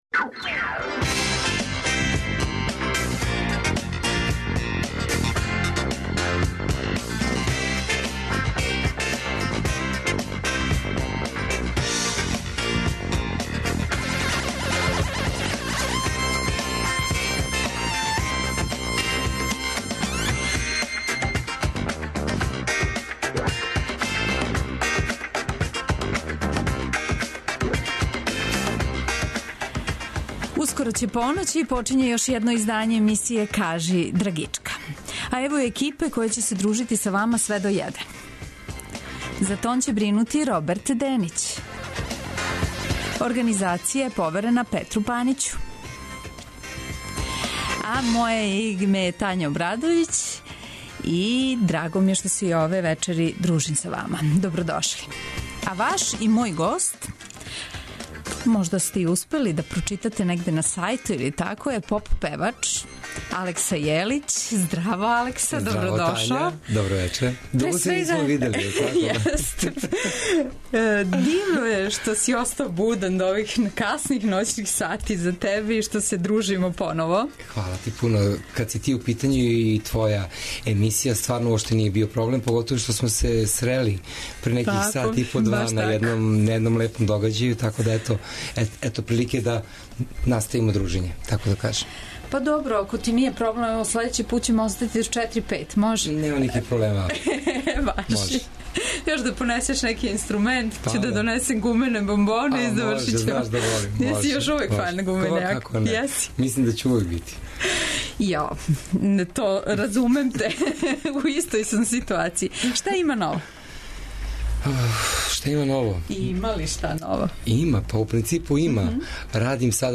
Гост емисије „Кажи драгичка“ од поноћи до један сат је поп певач Алекса Јелић. Слушаоци ће имати прилике да сазнају шта мисли о свом учешћу на избору за нашег представника на Песми Евровизије, али и о томе шта ново спрема.